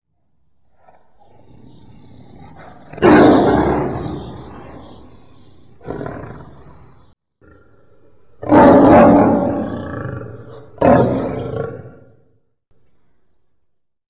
lion1.mp3